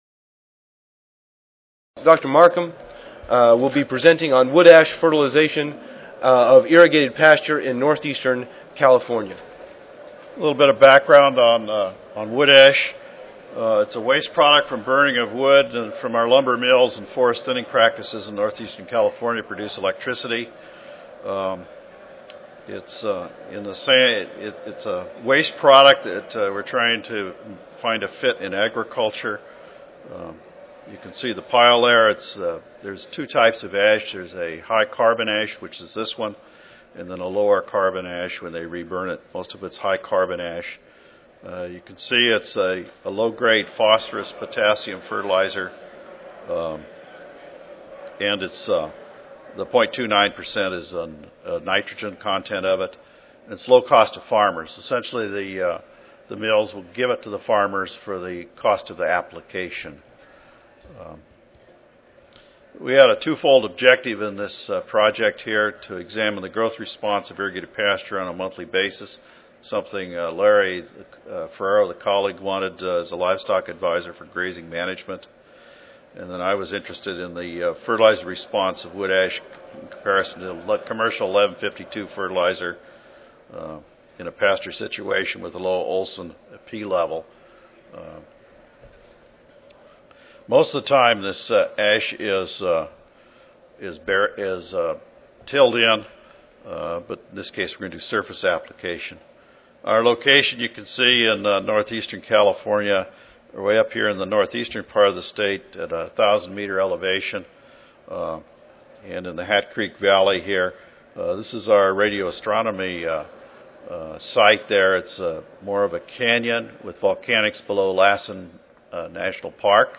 University of California-Davis Recorded Presentation Audio File